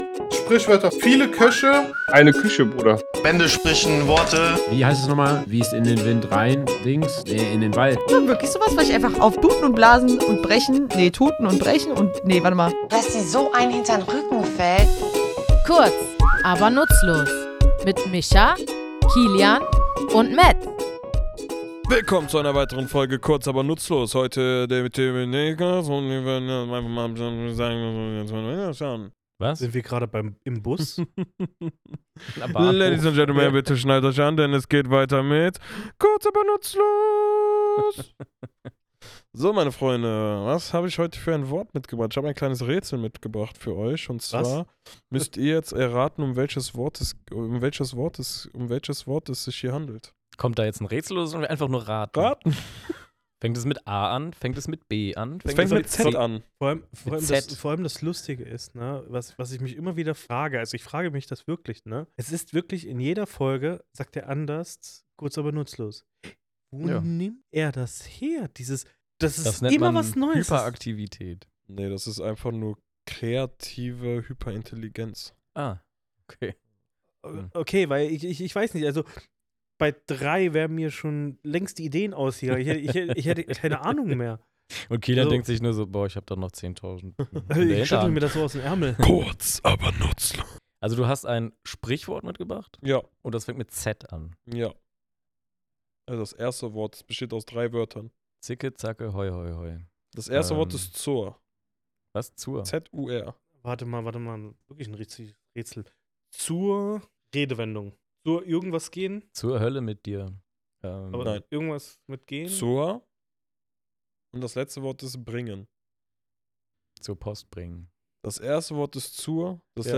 Woher stammt dieser Ausdruck, und warum wird er oft mit Jagd und Verfolgung in Verbindung gebracht? Wir, drei tätowierende Sprachforscher, nehmen euch in unserem Tattoostudio mit auf eine Reise durch die spannende Geschichte dieser Redensart.